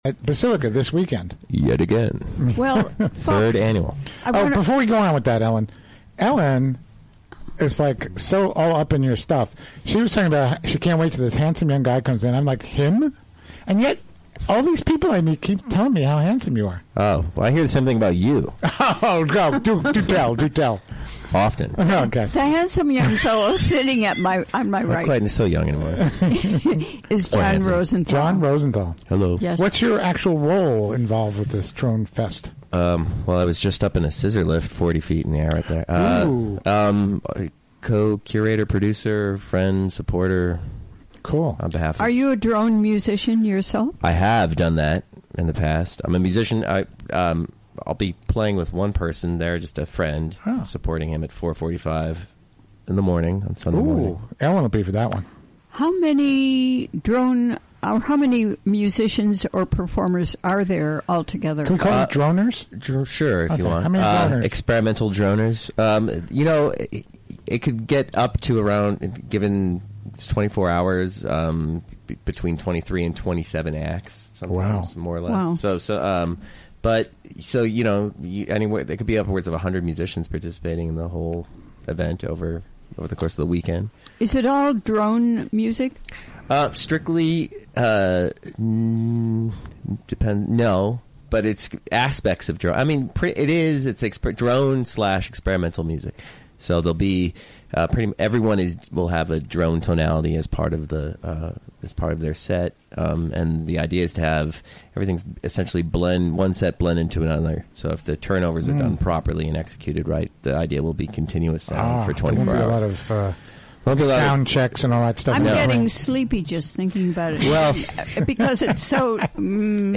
Recorded live during the WGXC Afternoon Show Thursday, Apr. 27, 2017.